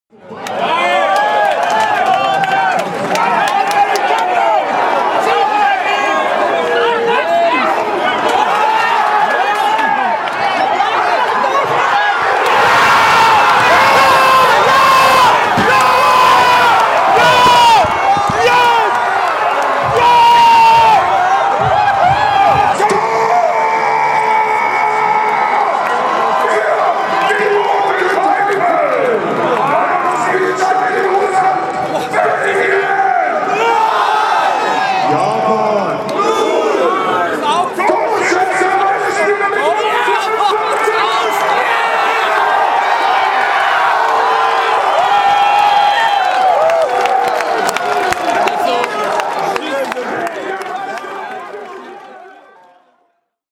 Die Stimmung kochte hoch beim 1:2-Anschlusstreffer von Vertonghen, schäumte über bei Fellainis Ausgleich und entlud sich gewaltig beim Kontertor in der letzten Aktion des Spiels. Die Emotionen bei diesem Angriff hören wir von der Entstehung an, los ging es bei Keeper Courtois: